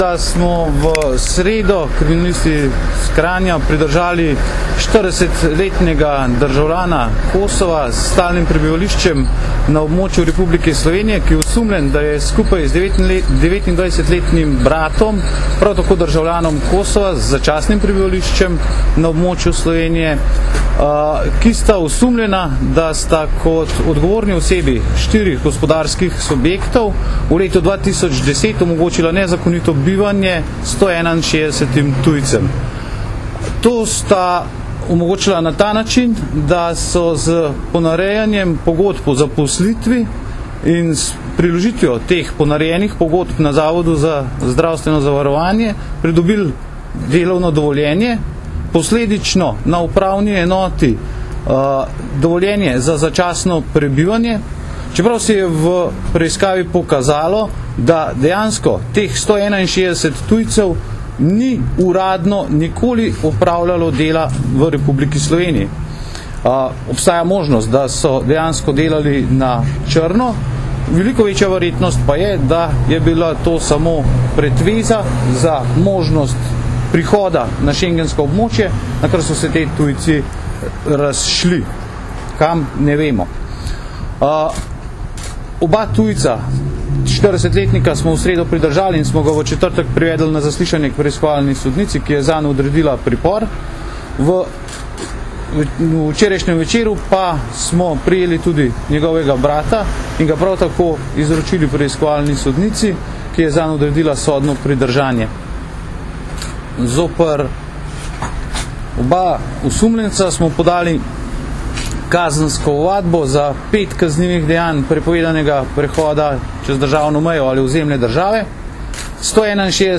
Objavljamo zvočni posnetek njegove izjave: